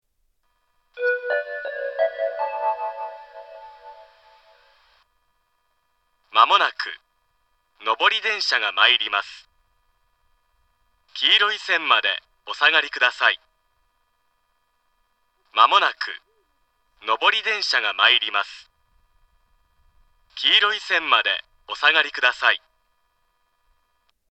スピーカーはすべてユニペックスマリンです。
仙石型（男性）
接近放送
仙石型男性の接近放送です。
周辺駅より遅れて放送更新しているので、放送が新しいです。